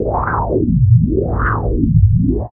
69 MD WIND-L.wav